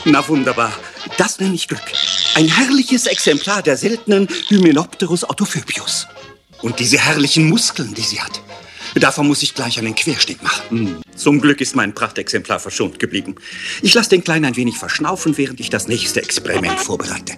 - Wissenschaftler